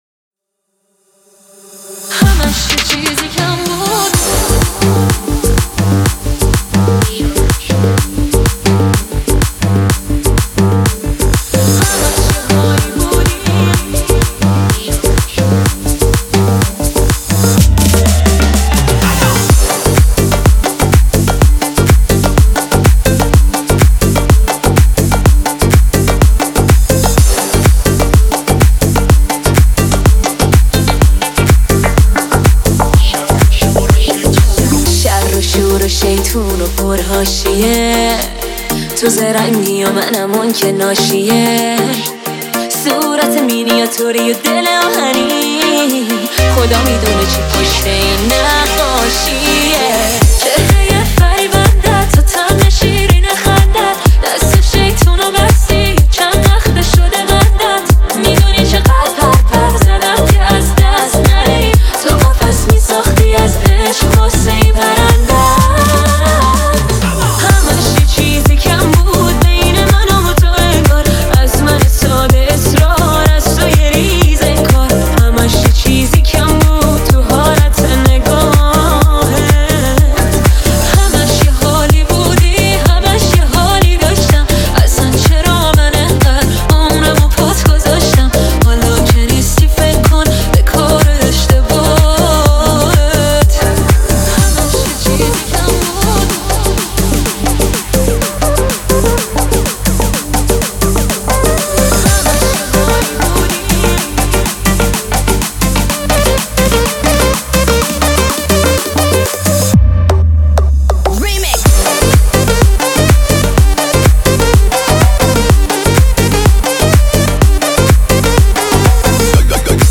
ریمیکس بیس دار